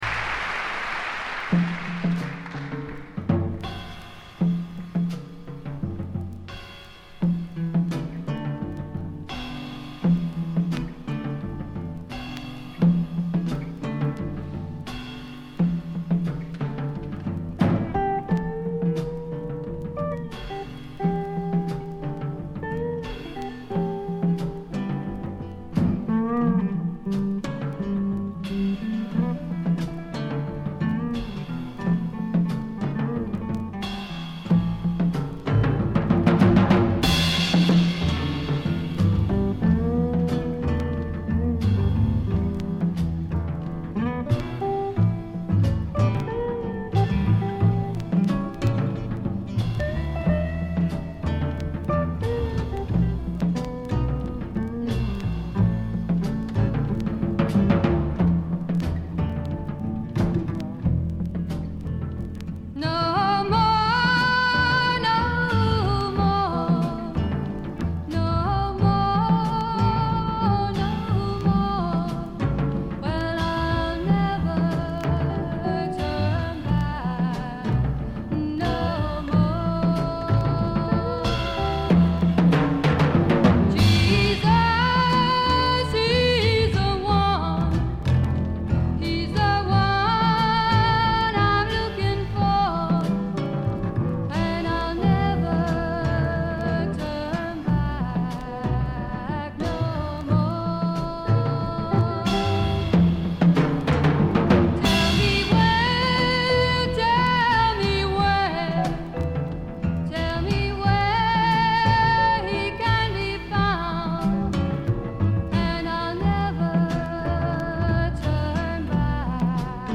A面はノイズがちょっと目立ちます。
試聴曲は現品からの取り込み音源です。